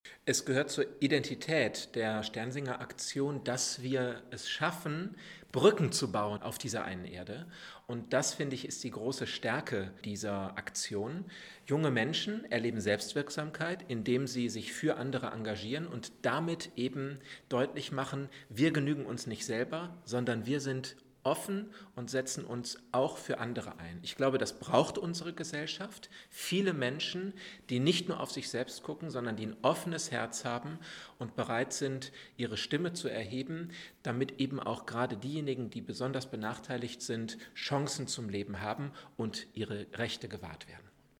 Pressemappe: Bundesweite Eröffnung in Paderborn - Pressekonferenz Audios